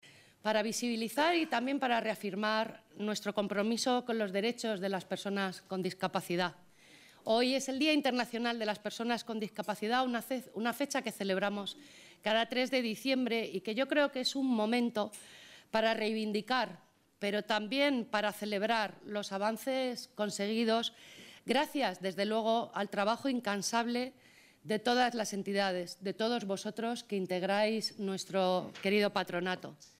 >> El salón de plenos del Ayuntamiento ha acogido el acto institucional celebrado con motivo del Día Internacional de las Personas con Discapacidad
Por su parte, la concejal de Servicios Sociales, Aurora Galisteo, ha afirmado que un día como este 3 de diciembre tiene que servir no sólo como celebración por todo lo logrado hasta ahora sino también para reivindicar mayores avances “contando siempre con la voz de los colectivos de Patronato”.